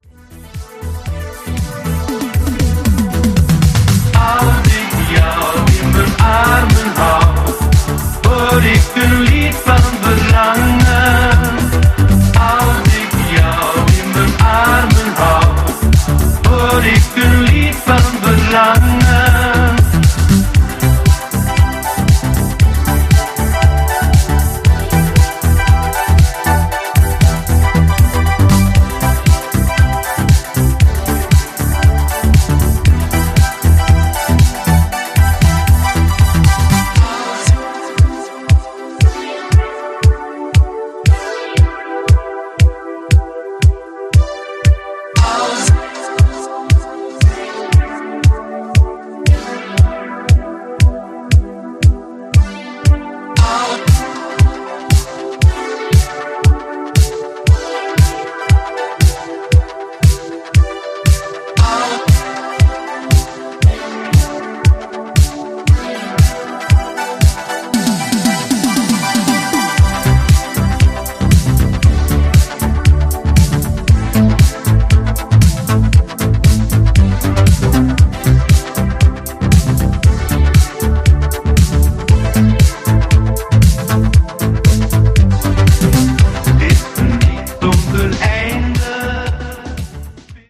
ジャンル(スタイル) DISCO / ITALO DISCO / BOOGIE